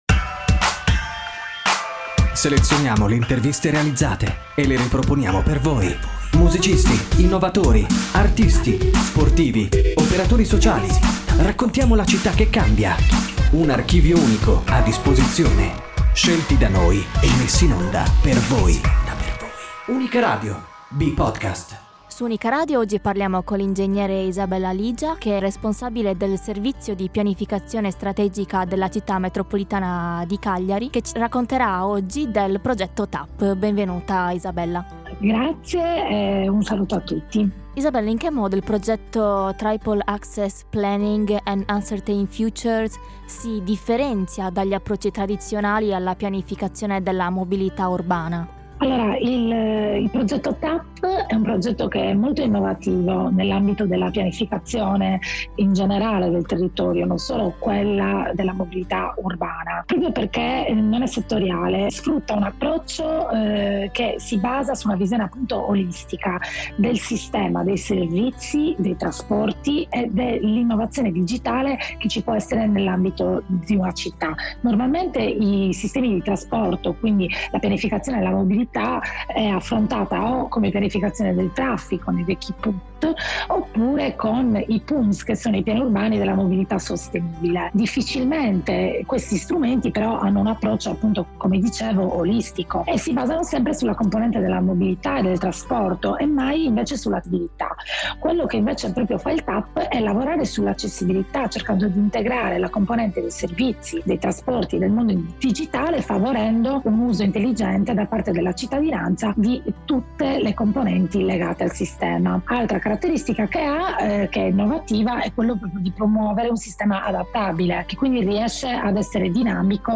Ai microfoni di Unica Radio